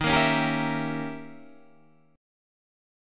D#7b9 chord